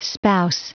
Prononciation du mot spouse en anglais (fichier audio)